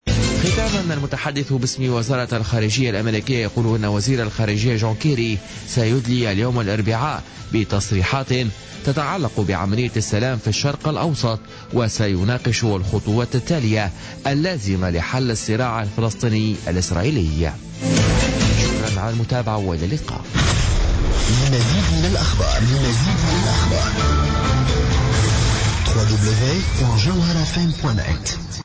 نشرة أخبار منتصف الليل ليوم الاربعاء 28 ديسمبر 2016